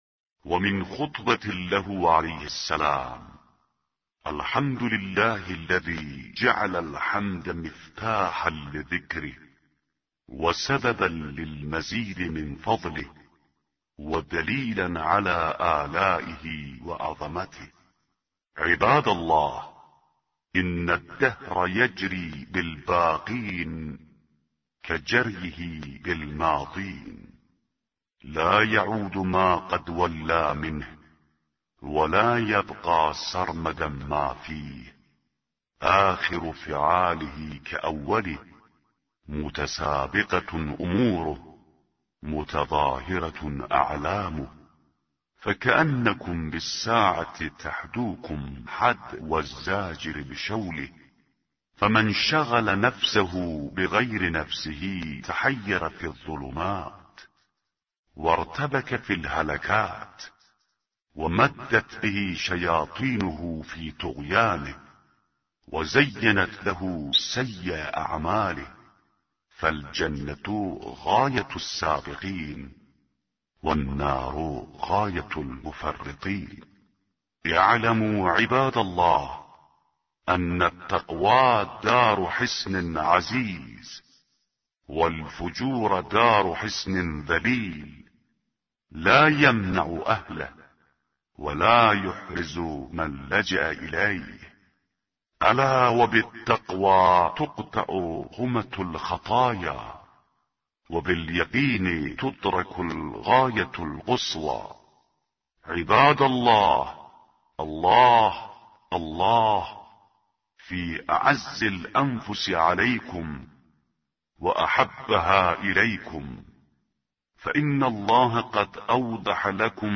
به گزارش وب گردی خبرگزاری صداوسیما؛ در این مطلب وب گردی قصد داریم، خطبه شماره ۱۵۷ از کتاب ارزشمند نهج البلاغه با ترجمه محمد دشتی را مرور نماییم، ضمنا صوت خوانش خطبه و ترجمه آن ضمیمه شده است: